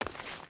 Stone Chain Run 1.wav